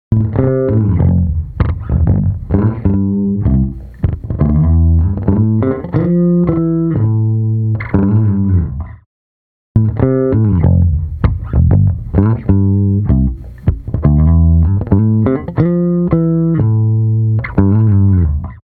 Unique Multi-Tap Delay
UltraTap | Bass | Preset: UltraSlap
Bass-UltraSlap.mp3